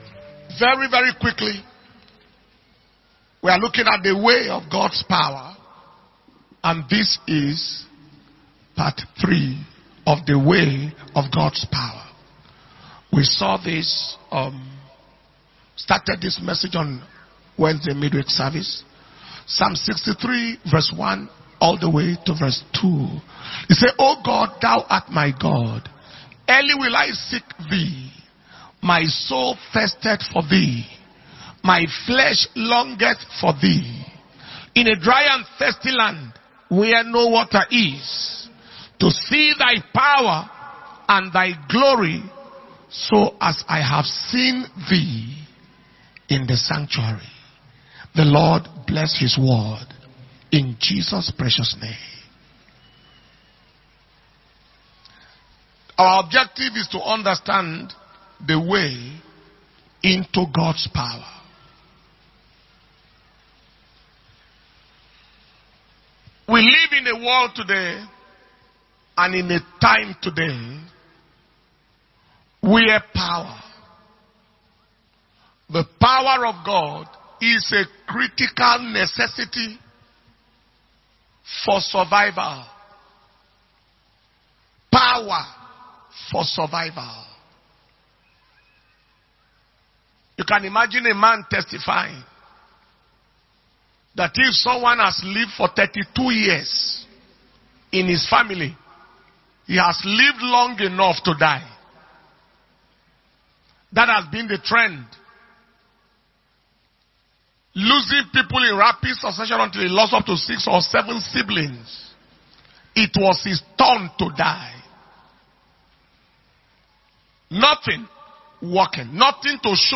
November 2022 Impartation Service